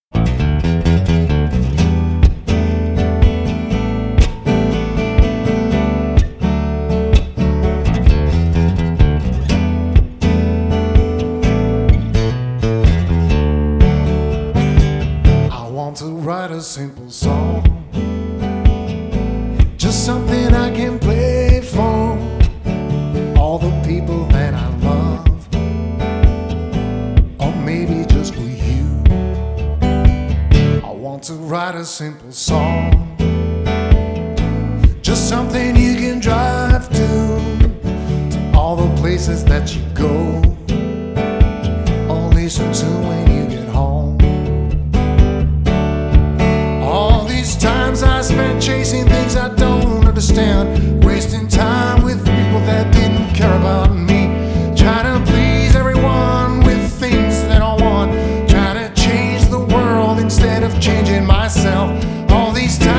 • Singer/songwriter